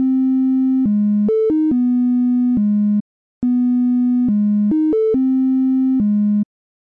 Tag: 140320 未来的车库 2步骤 回响贝斯 旋律